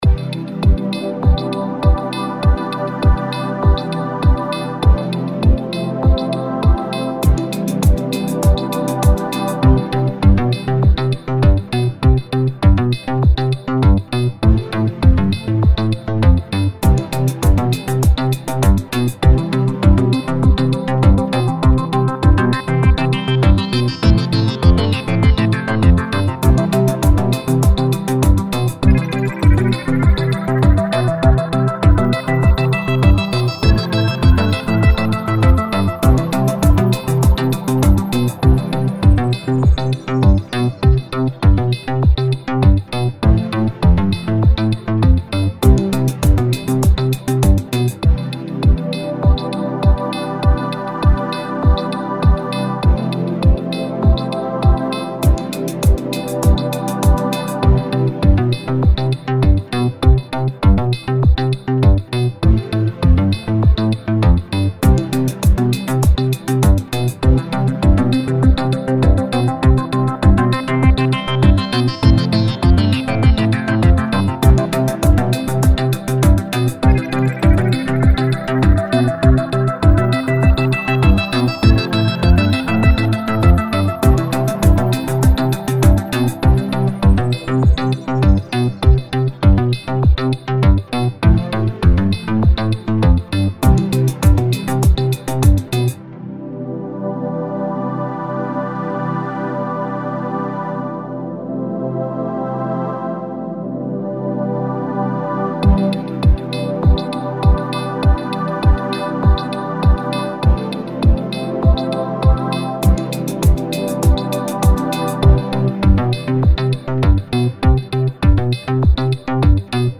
Релакс, який заряджає smile 39 39 39